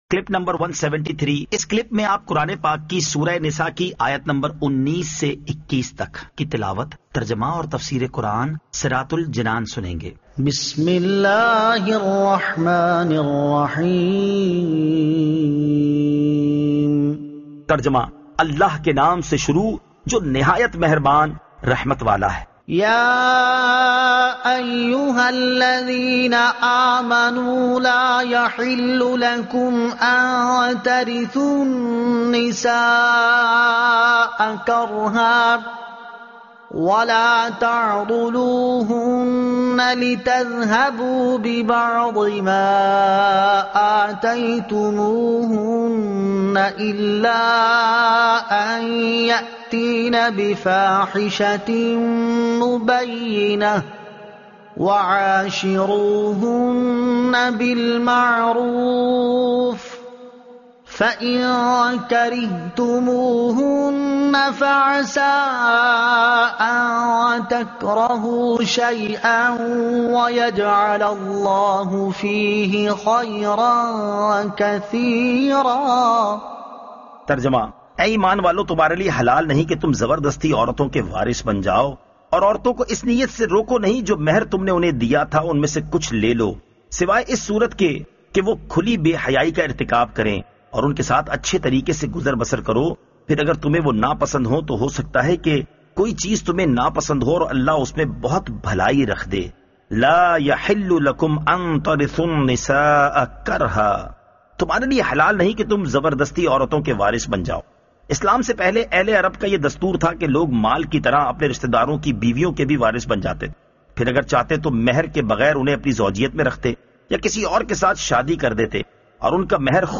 Surah An-Nisa Ayat 19 To 21 Tilawat , Tarjuma , Tafseer